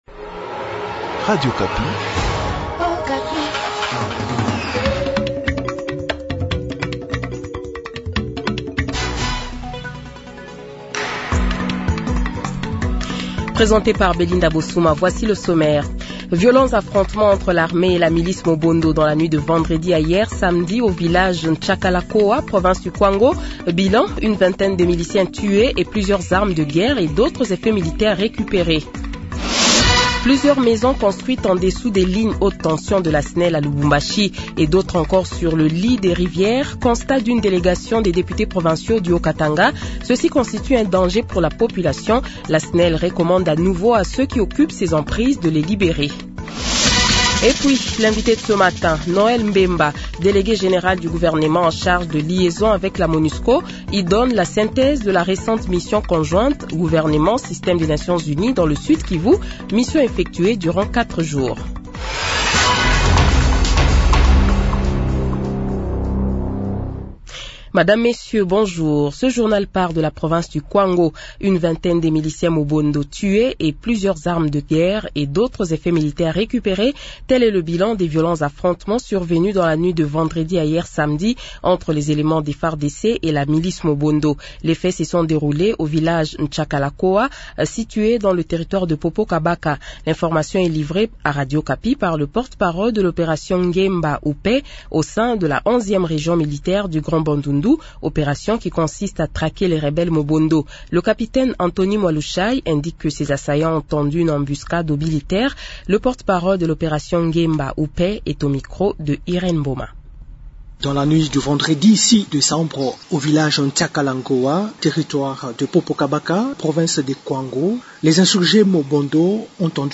Journal Francais Matin
Le Journal de 7h, 08 Decembre 2024 :